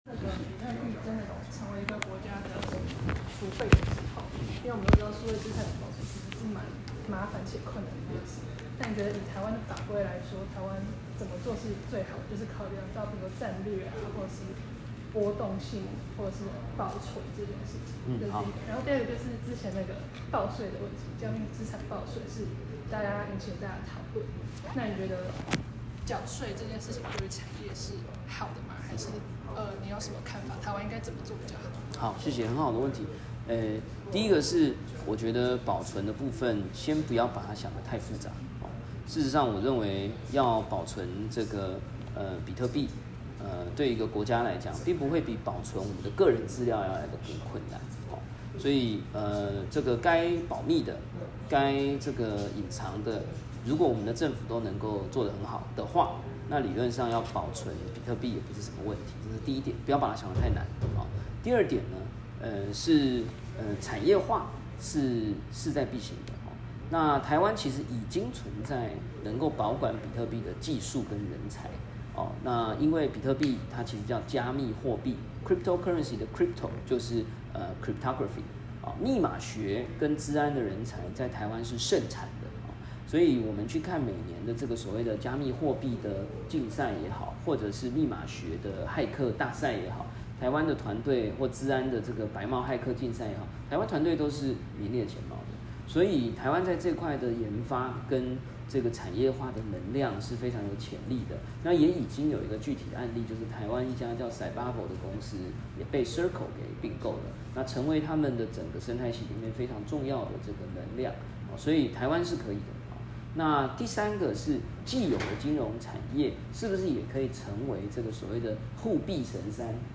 比特幣儲備論壇－會後記者聯訪
時間：2025-03-13 與會人士：記者 - 1、葛如鈞委員、記者 - 2、記者 - 3、記者 - 4、記者 - 5